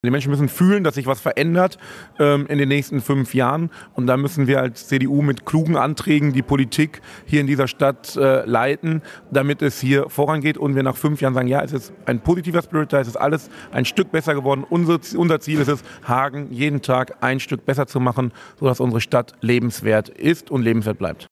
Dennis RehbeinCDU Hagen Parteitag Dennis Rehbein